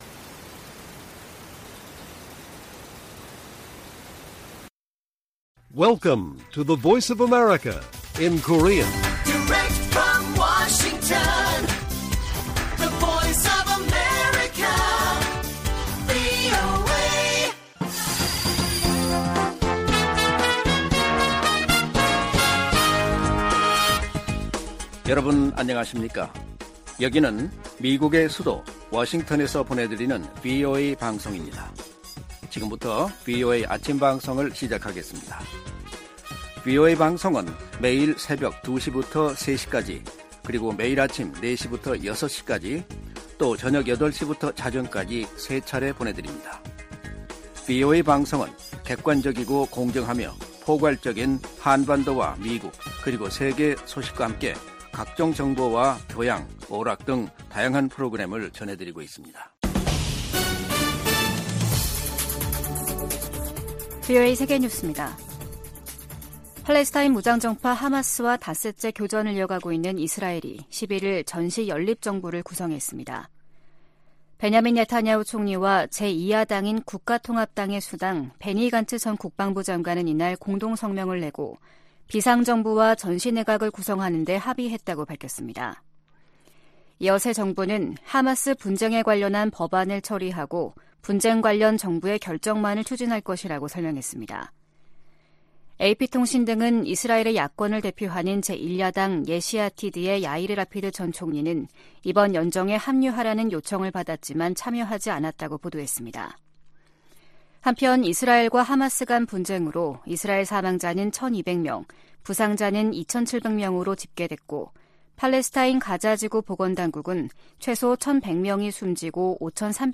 세계 뉴스와 함께 미국의 모든 것을 소개하는 '생방송 여기는 워싱턴입니다', 2023년 10월 12일 아침 방송입니다. '지구촌 오늘'에서는 이스라엘과 팔레스타인 무장 정파 하마스의 무력충돌 닷새째 양측 사망자 2천100명을 넘어선 소식 전해드리고, '아메리카 나우'에서는 새 하원의장 선출에 난항을 겪는 이야기 살펴보겠습니다.